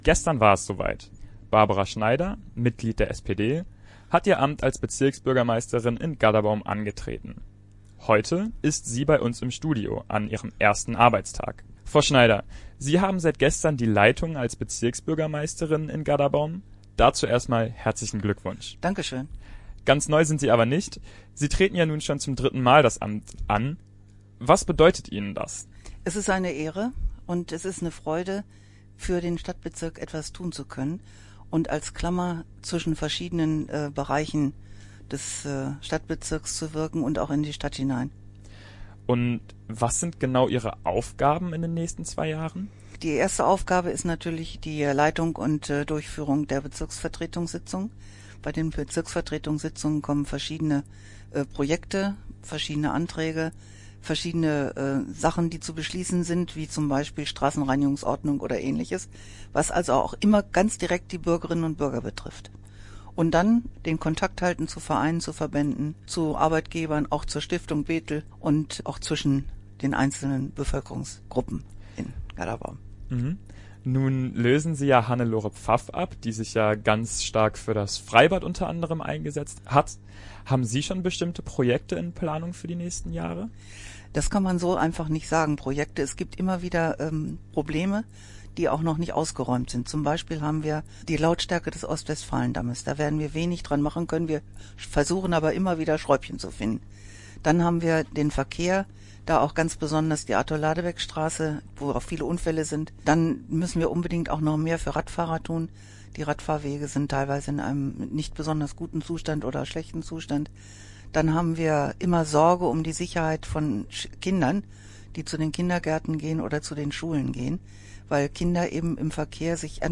Eine der ersten Amtshandlungen war ein Interview bei Antenne Bethel.